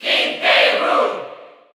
Category: Crowd cheers (SSBU) You cannot overwrite this file.
King_K._Rool_Cheer_Spanish_NTSC_SSBU.ogg